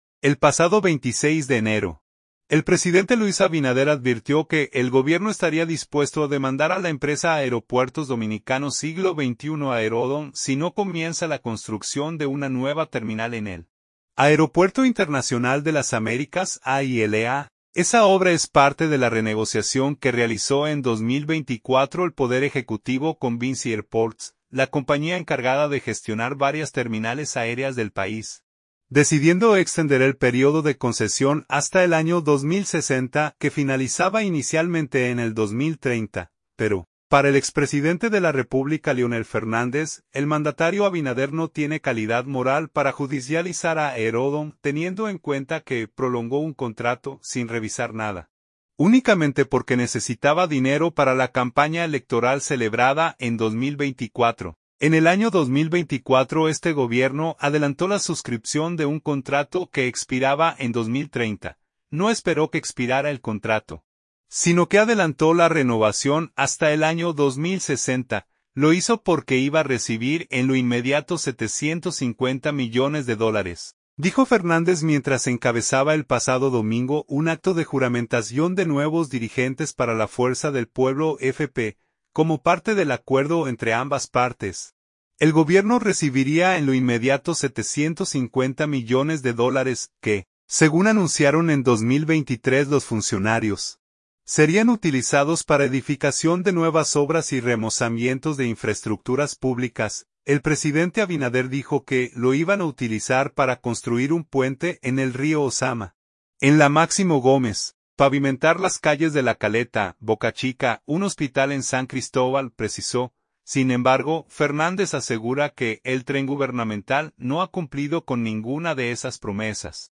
“En el año 2024 este gobierno adelantó la suscripción de un contrato que expiraba en 2030. No esperó que expirara el contrato, sino que adelantó la renovación hasta el año 2060. Lo hizo porque iba a recibir en lo inmediato 750 millones de dólares”, dijo Fernández mientras encabezaba el pasado domingo un acto de juramentación de nuevos dirigentes para la Fuerza del Pueblo (FP).